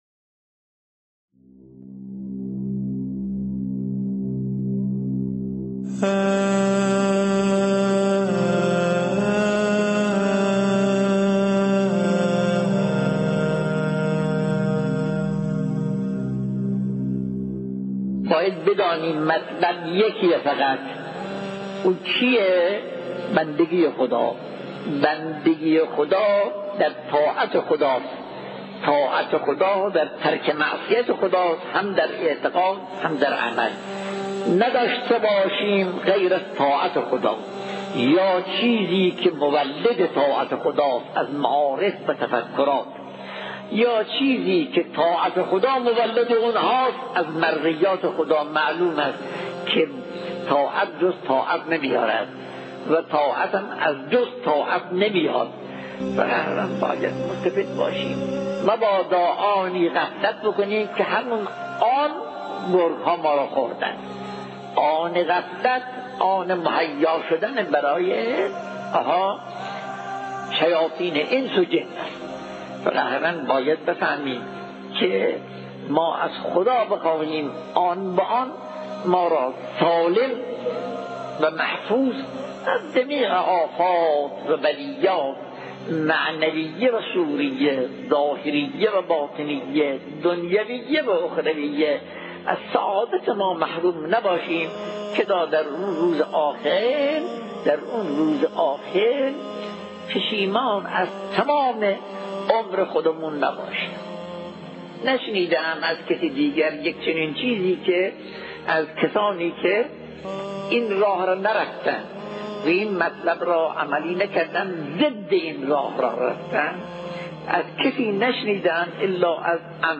مجموعه پادکست «روایت بندگی» با کلام اساتید بنام اخلاق به کوشش ایکنا گردآوری و تهیه شده است، که سومین قسمت این مجموعه با کلام مرحوم آیت‌الله بهجت(ره) با عنوان «بندگی و طاعت خداوند» تقدیم مخاطبان گرامی ایکنا می‌شود.